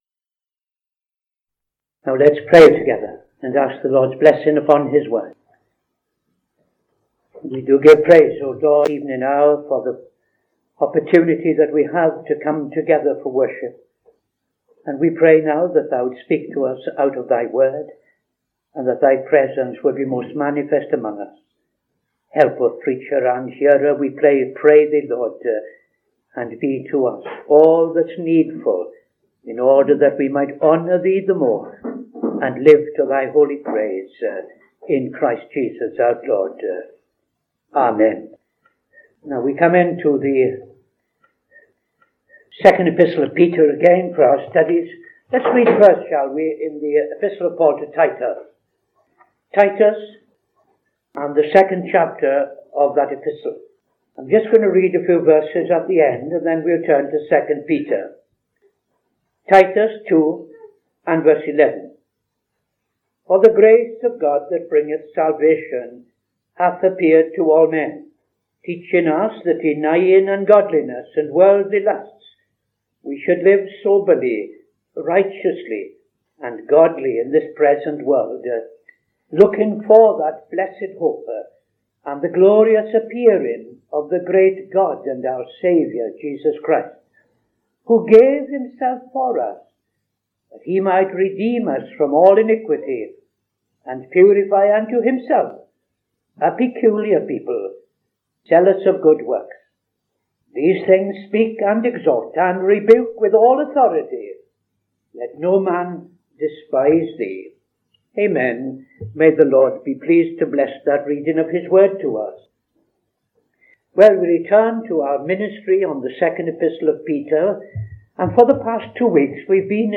Saturday Sermon - TFCChurch
Opening Prayer and Reading Titus 2:11-15; II Peter 3:3-4